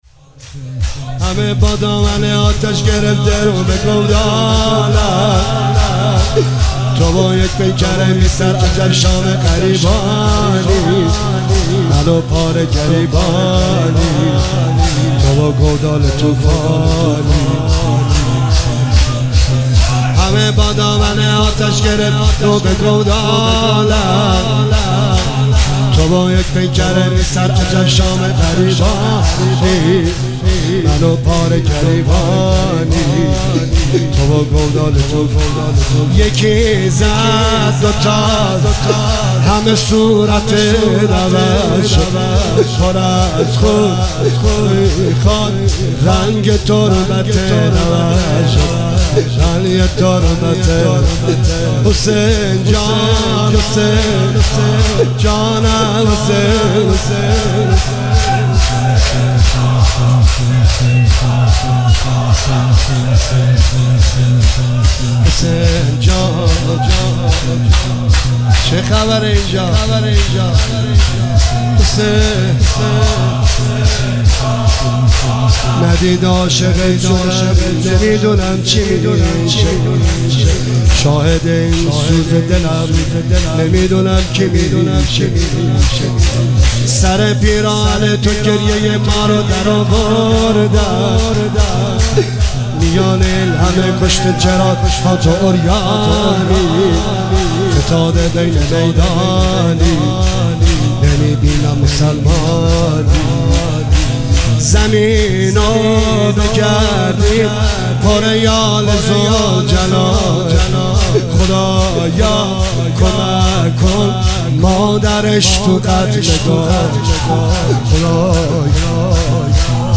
روز ششم محرم 1404